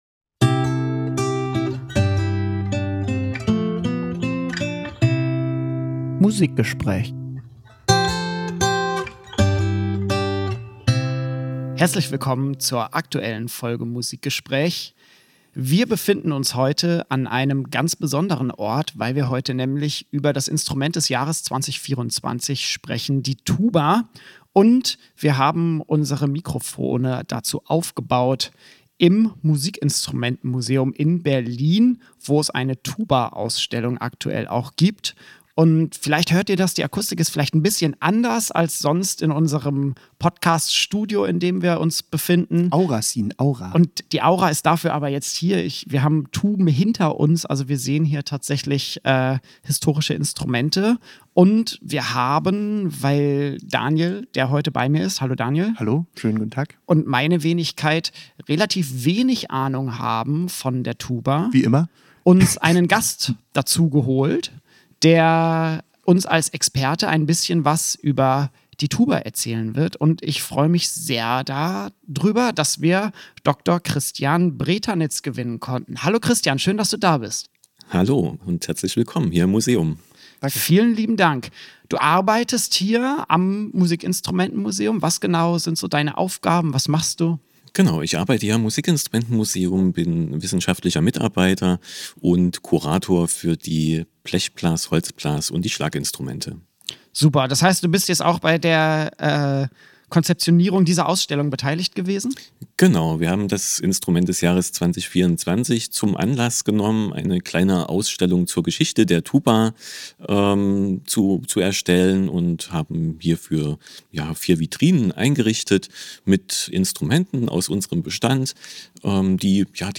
Musikgespräch ist zu Gast im Musikinstrumentenmuseum Berlin.